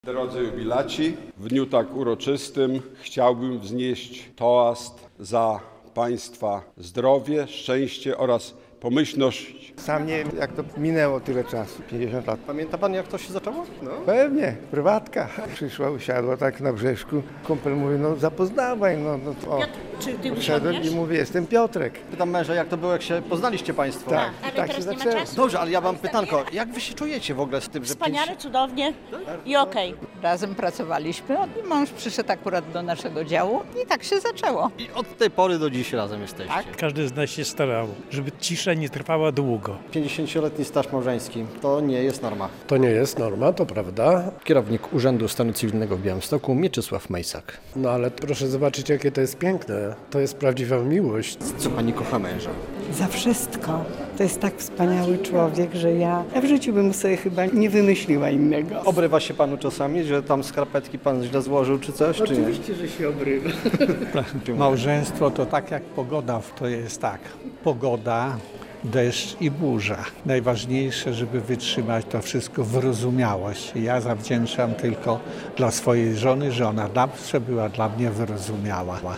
Dziś, po 50 latach wspólnego małżeństwa, 146 par z Białegostoku w Auli Magna Pałacu Branickich z rąk prezydenta miasta otrzymało okolicznościowe medale za długi staż małżeński.
Pytani przez nas jubilaci podkreślają, że mimo kryzysów w związku zawsze starają się znaleźć wyjście z impasu.